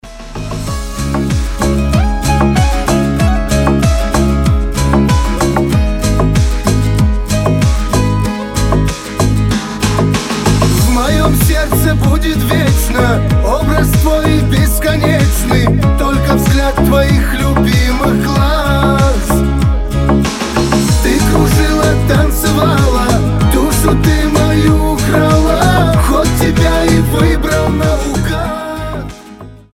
• Качество: 320, Stereo
гитара
мужской голос
кавказские